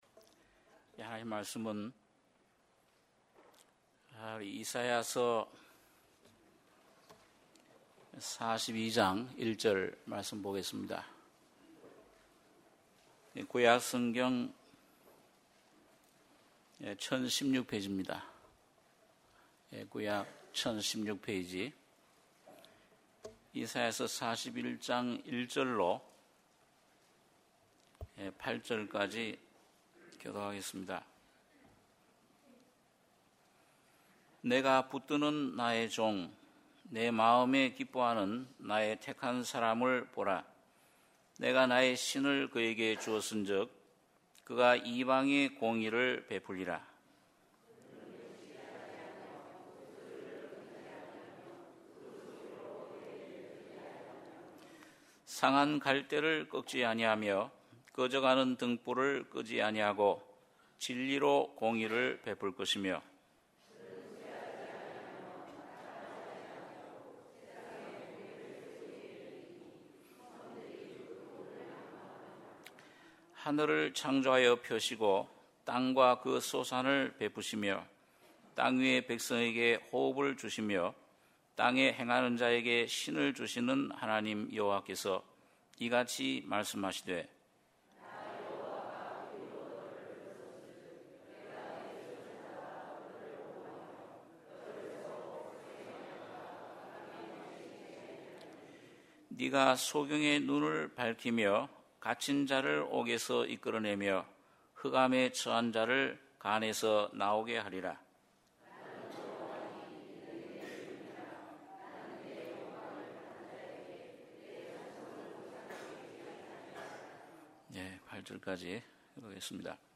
주일예배 - 이사야 42장 1절~8절 1부